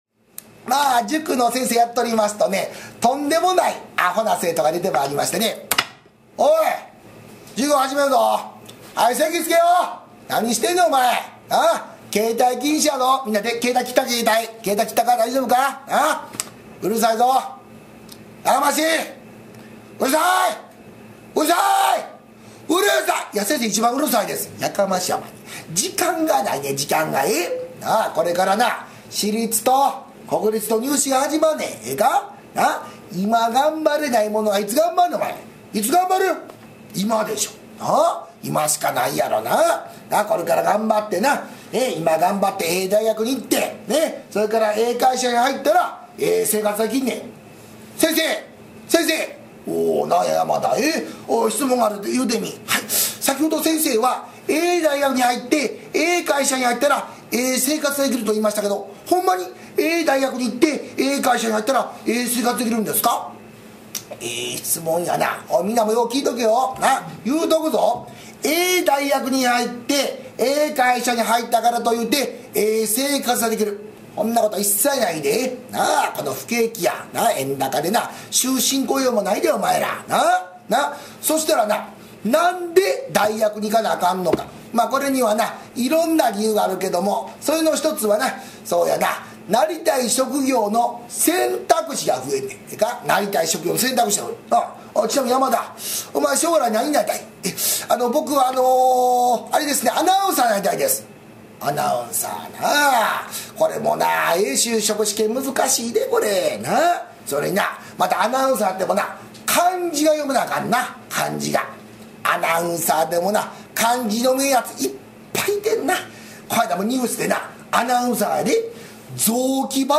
こども落語